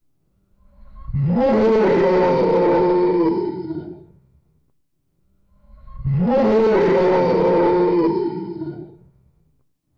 In this work, we introduce SonicMotion, the first end-to-end latent diffusion framework capable of generating FOA audio with explicit control over moving sound sources.
Prompt: "A lion roars from the back right and below"